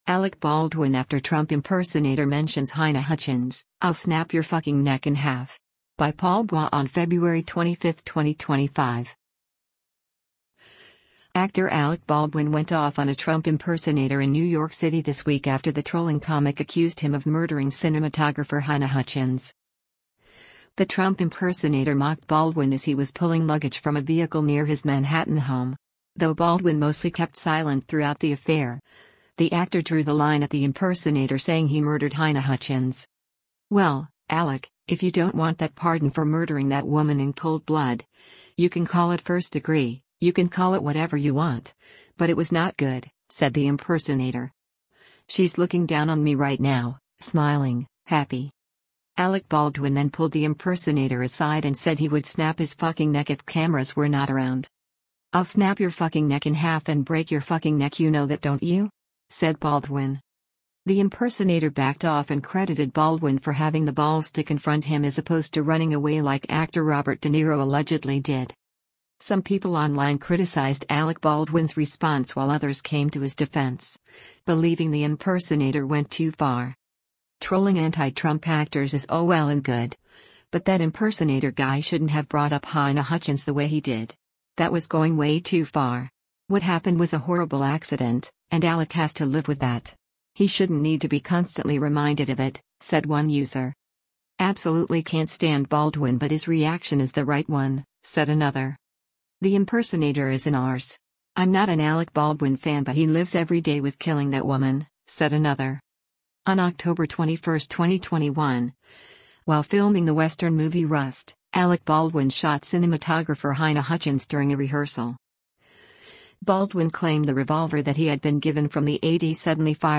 The Trump impersonator mocked Baldwin as he was pulling luggage from a vehicle near his Manhattan home.